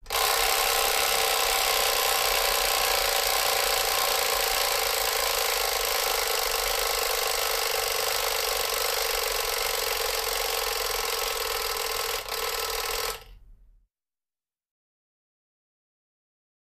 Clock Alarm Old 2; Rings With Ratty Fast Clunks